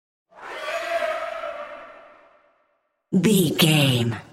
Sound Effects
Atonal
scary
ominous
eerie